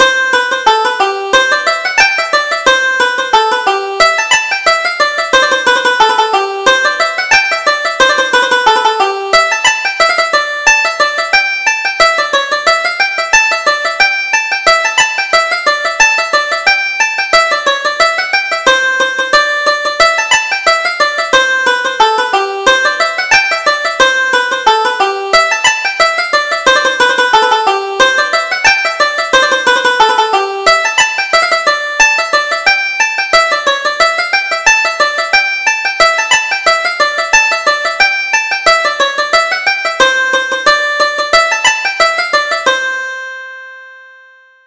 Reel: Timothy Downing